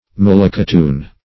malacotoon - definition of malacotoon - synonyms, pronunciation, spelling from Free Dictionary Search Result for " malacotoon" : The Collaborative International Dictionary of English v.0.48: Malacotoon \Mal`a*co*toon"\, n. (Bot.)